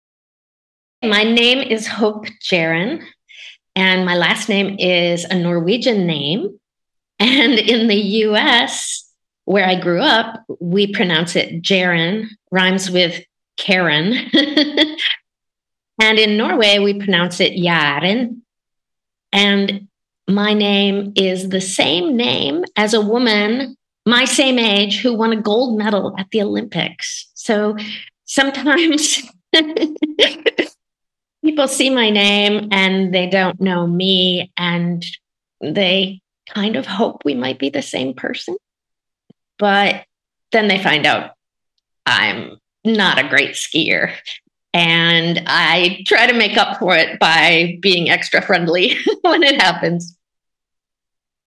Audio Name Pronunciation
A recording introducing and pronouncing Hope Jahren.